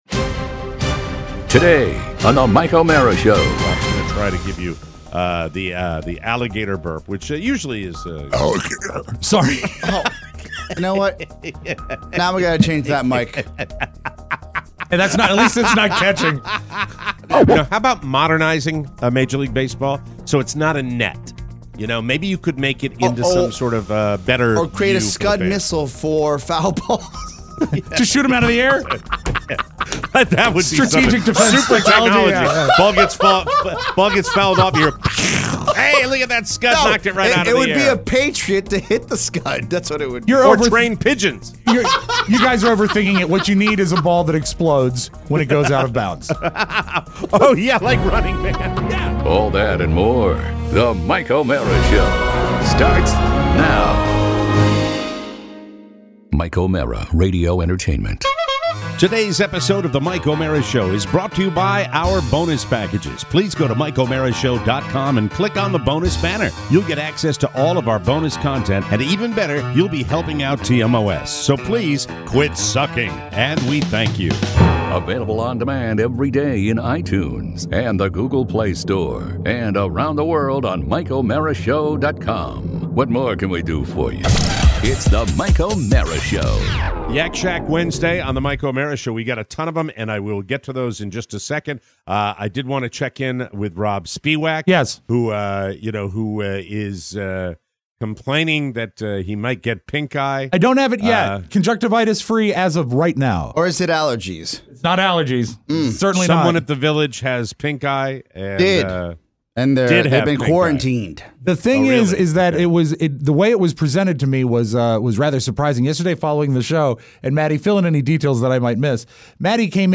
No pink eye… yet! Plus, Mr. Chappelle, jazz hands, Rush… and your calls.